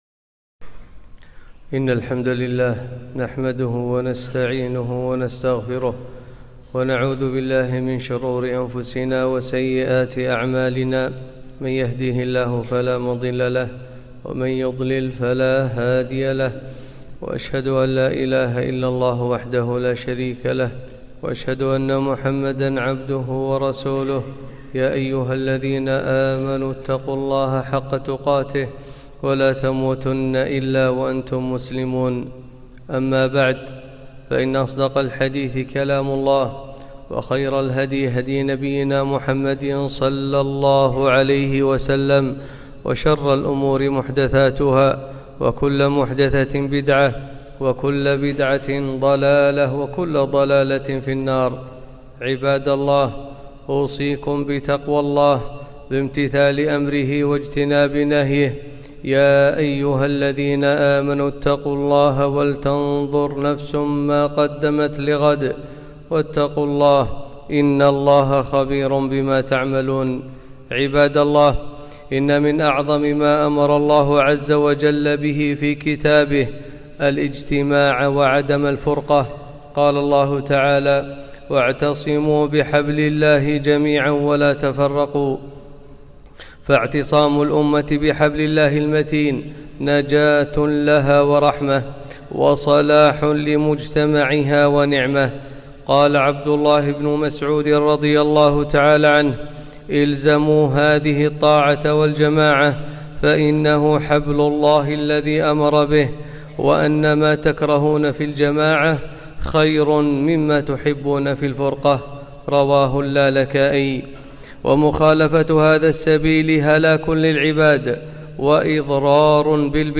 خطبة - لزوم الجماعة وطاعة ولي الأمر